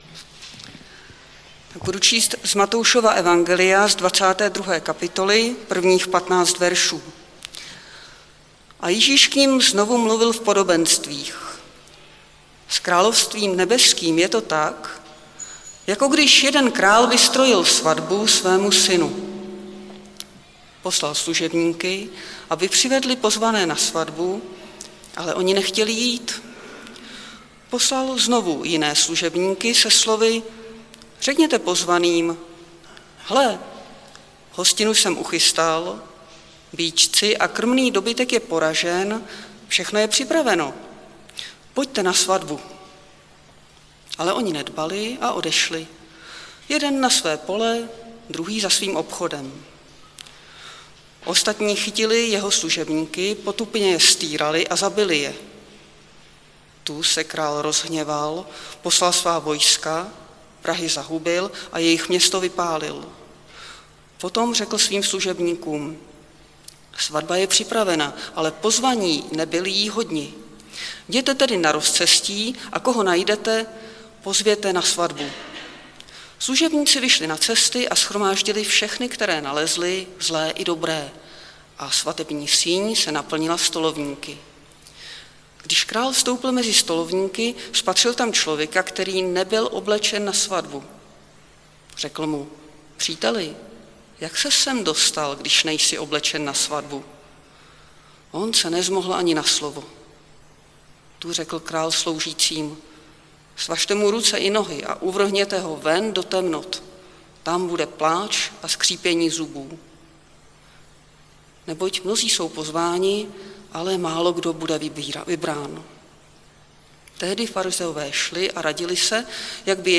Nedělní kázání – 28.11.2021 Podobenství o hostině